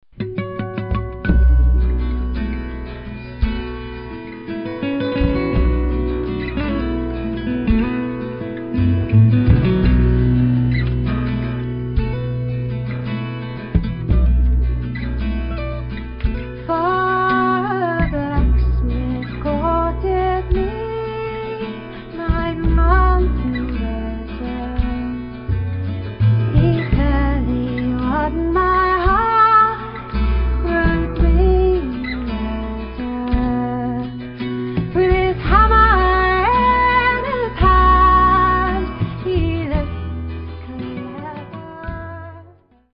vocals, fiddle
interesting waltz tempo version